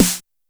Snares
Snare43.wav